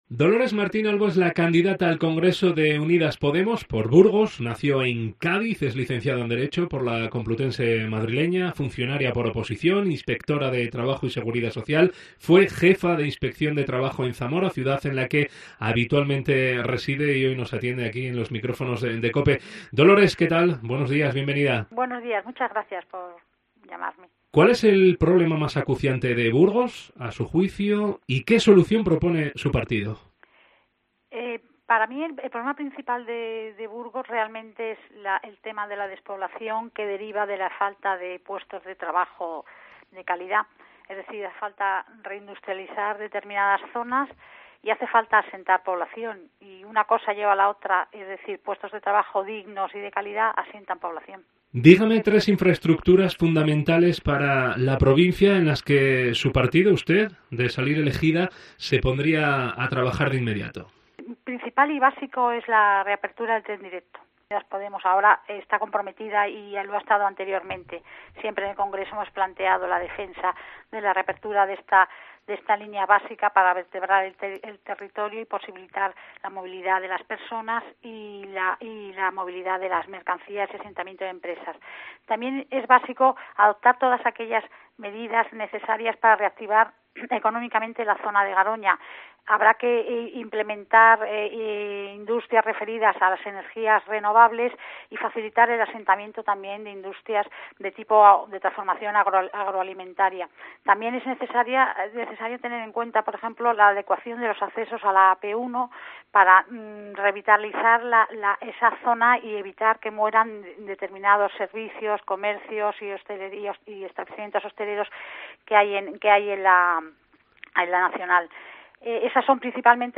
responde a las preguntas